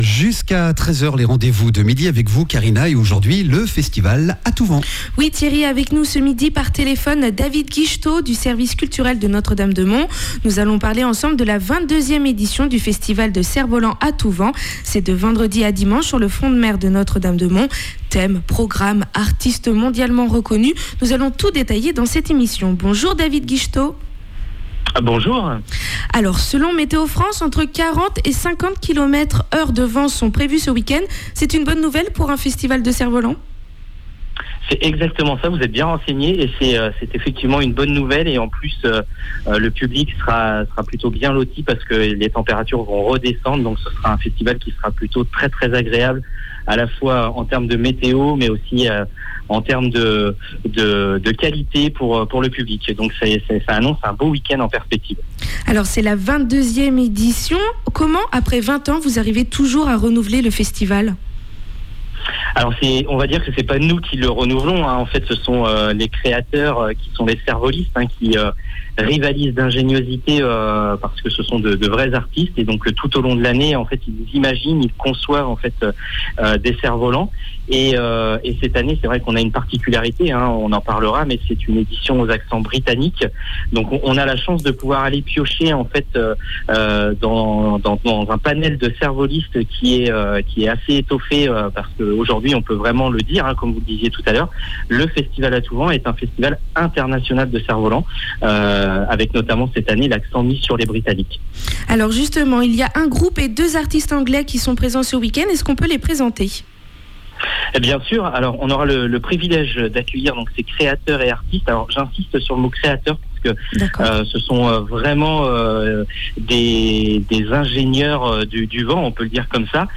que nous avons en direct par téléphone.